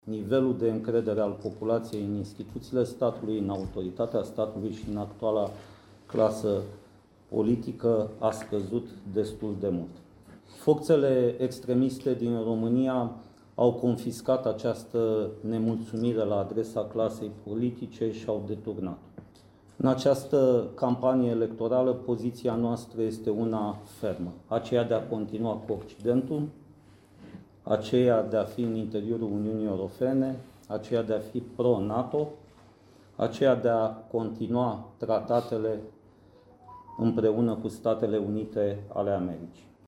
CONSTANȚA: Conferință de presă la sediul PNL. Ce subiecte au fost abordate